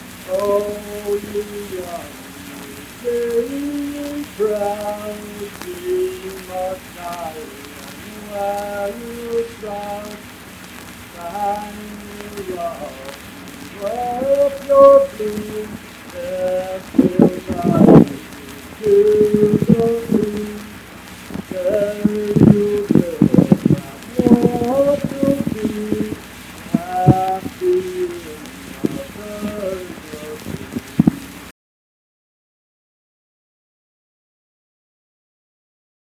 Unaccompanied vocal music performance
Verse-refrain 1(6).
Hymns and Spiritual Music
Voice (sung)